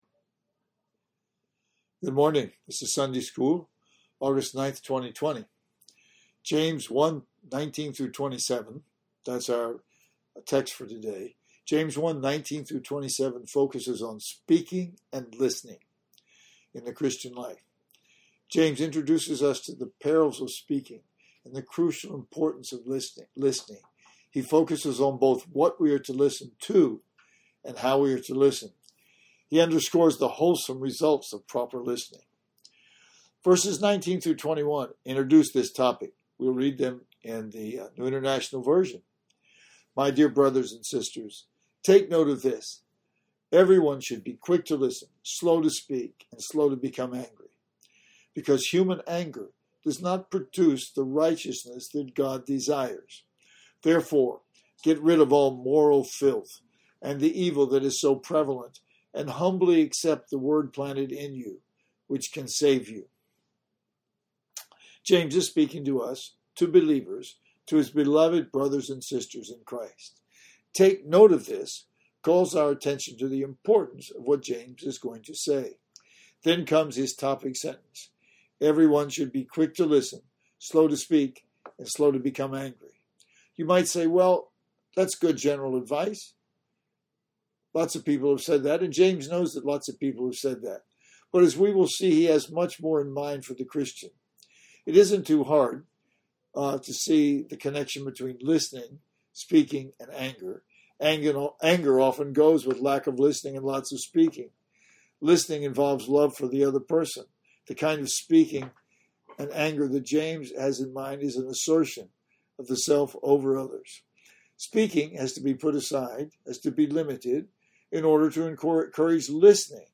Listening, Speaking, and the Religion that God Our Father Accepts. Sunday School, Aug 9, 2020. James 1:19-27.